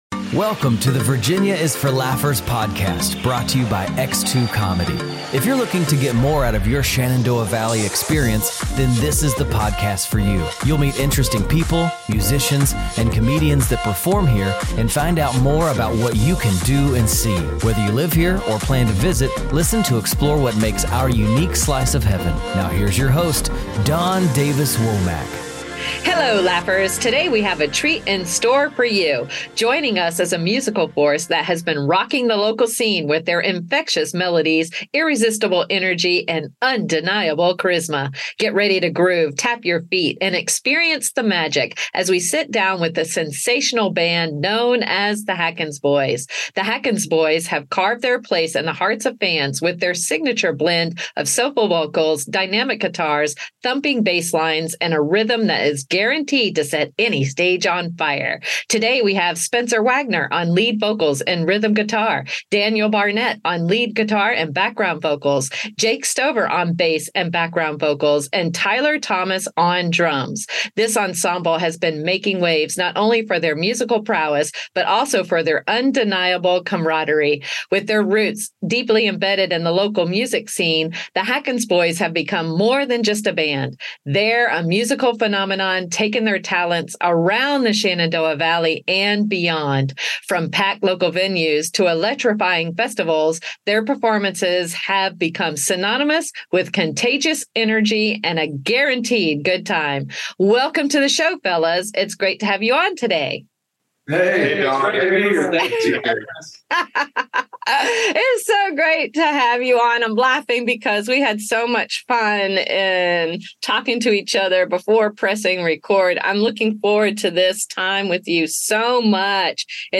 In our interview, we recommend checking out The Hackens Boys' YouTube channel and invite Laughers to be a part of a subscriber goal made during the interview! Plus, find out how each band member brings a unique flair, blending 90s alternative, Texas country, soulful Southern rock, and more.